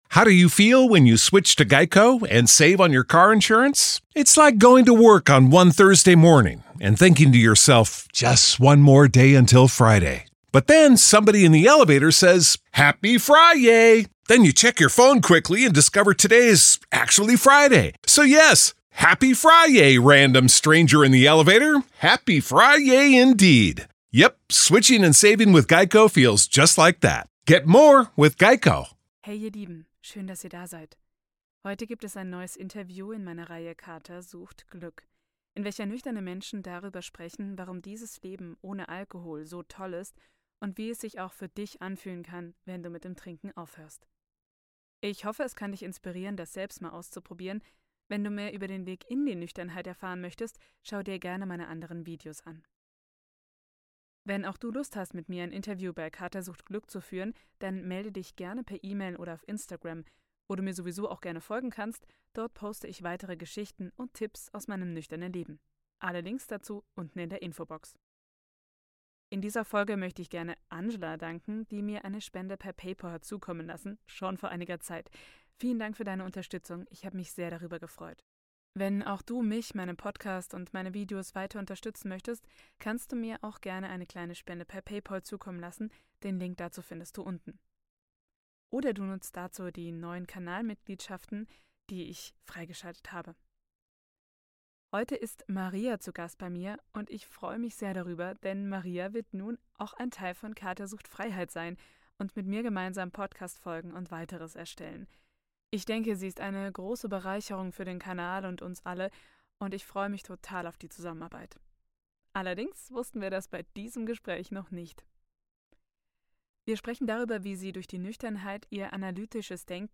Ich hoffe, das Interview gefällt euch - schreibt mir das gerne mal in die Kommentare!